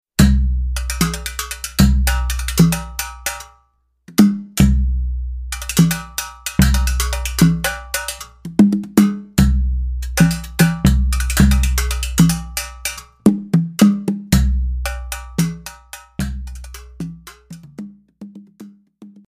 Multi-Percussion